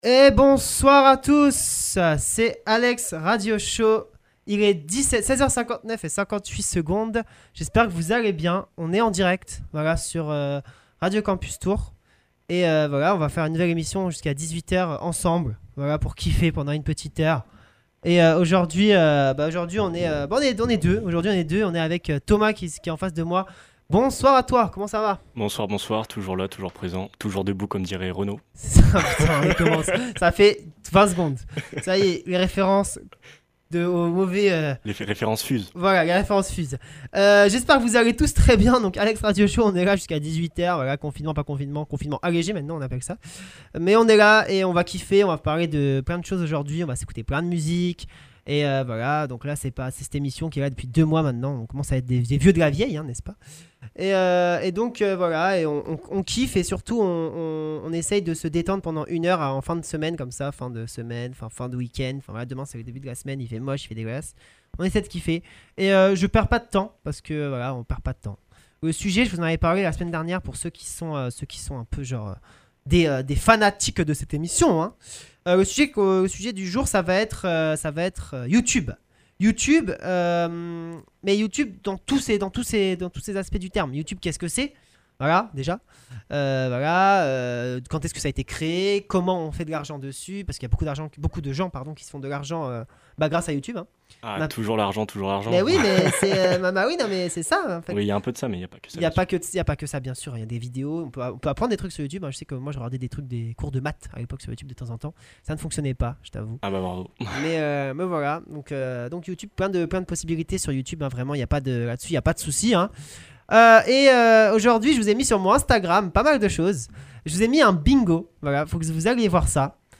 Des pauses musicales rythmeront le show toutes les 8 à 10 minutes environ !